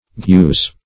Search Result for " guze" : The Collaborative International Dictionary of English v.0.48: Guze \Guze\ (g[=u]z), n. [Cf. Gules .]